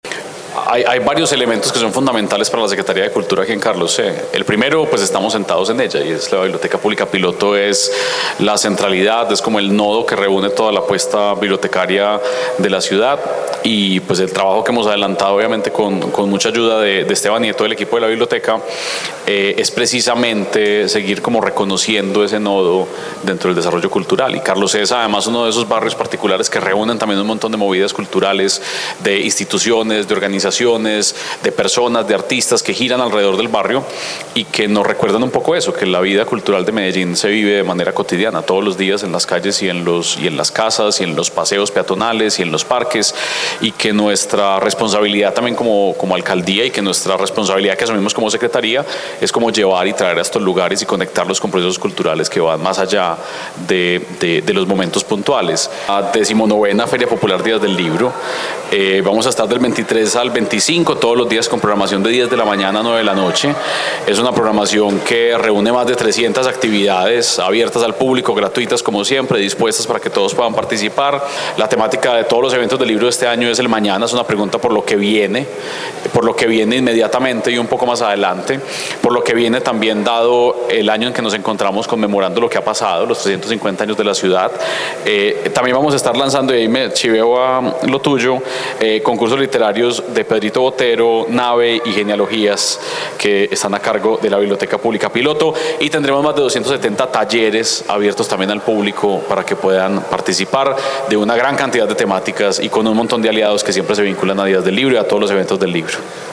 Audio-Declaraciones-del-secretario-de-Cultura-Ciudadana-Santiago-Silva-Jaramillo.mp3